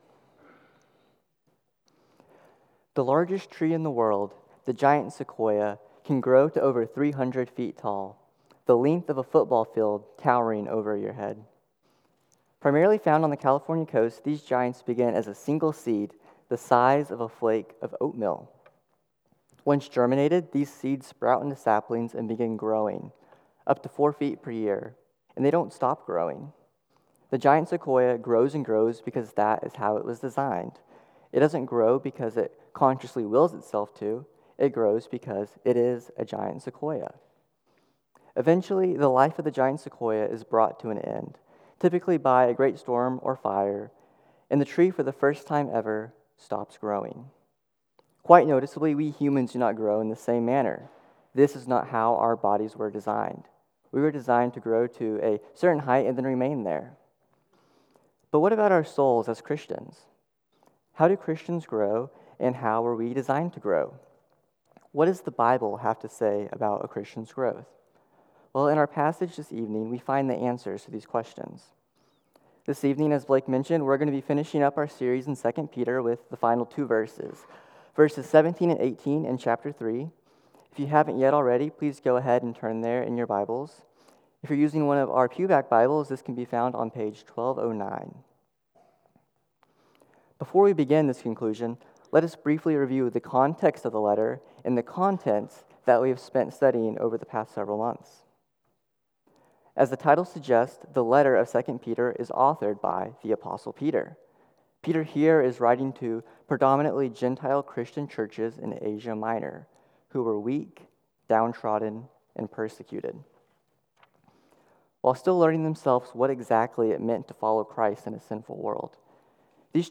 CCBC Sermons 2 Peter 3:17-18 Feb 17 2025 | 00:26:22 1x 00:00 / 00:26:22 Subscribe Share Apple Podcasts Spotify Overcast RSS Feed Share Link Embed